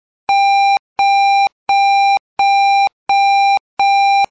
NAST　小型前後進メロディーアラーム
ブザー音